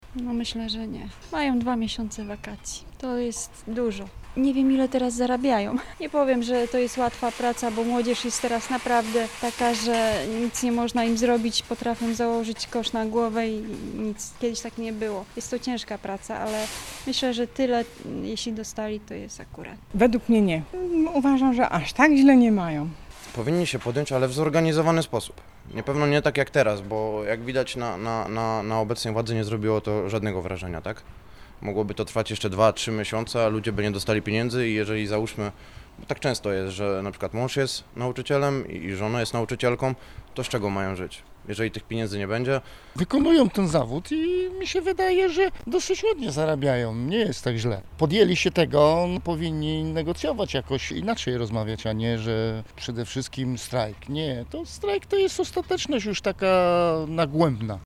Czy nauczyciele powinni znów podjąć akcję strajkową i dochodzić swoich praw kosztem dzieci? – z takim pytaniem zwróciliśmy się do zielonogórzan: